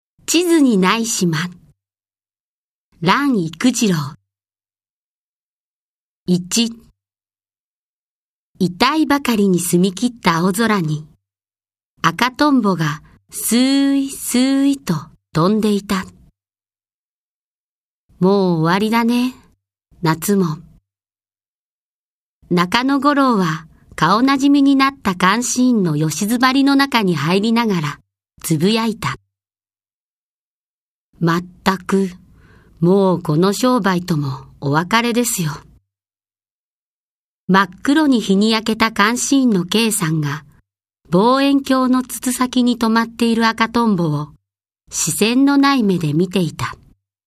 朗読ＣＤ　朗読街道131「地図にない島」蘭郁二郎
朗読街道は作品の価値を損なうことなくノーカットで朗読しています。